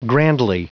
Prononciation du mot grandly en anglais (fichier audio)
Prononciation du mot : grandly